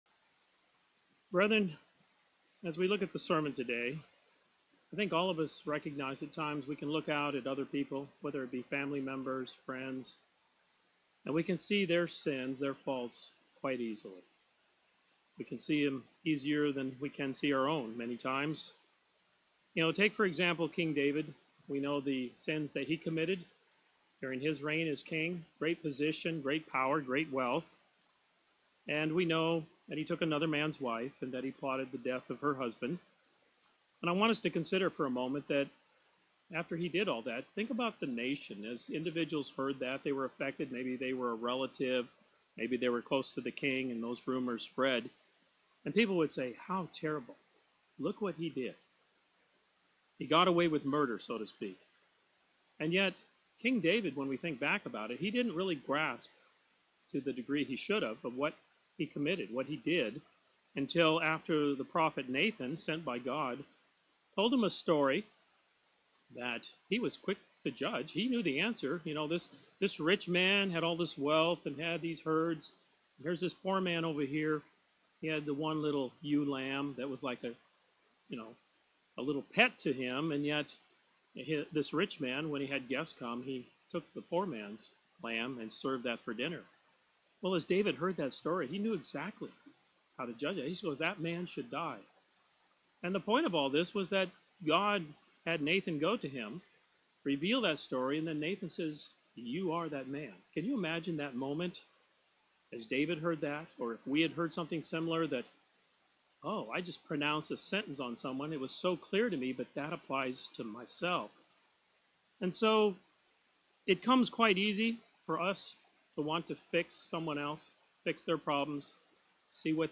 God has instructed His children to evaluate their lives and then make an effort in changing for the better. This sermon focuses on several questions that followers of Christ should be ready to answer – will our response be pleasing to God?